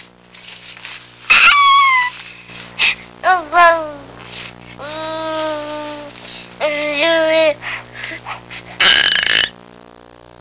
Happy Baby Sound Effect
Download a high-quality happy baby sound effect.
happy-baby-1.mp3